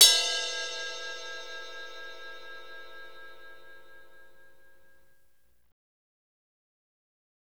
CYM F S BE0T.wav